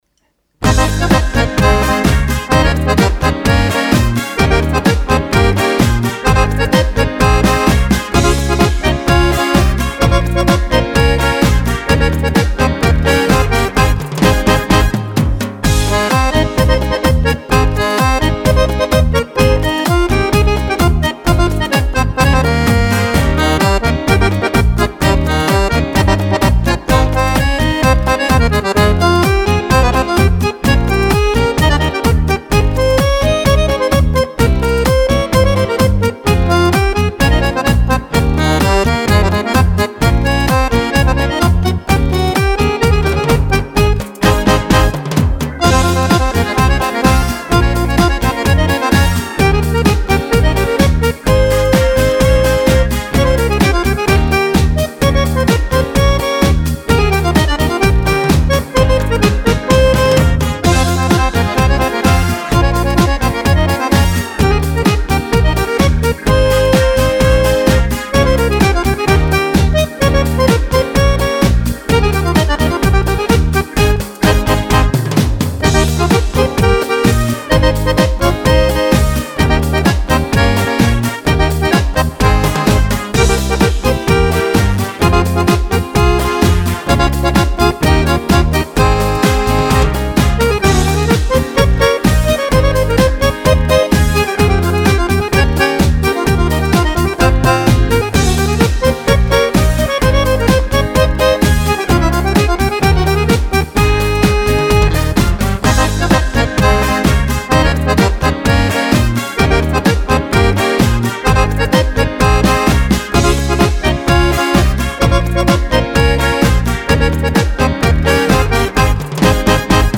Cha cha cha
Cha cha cha per Fisarmonica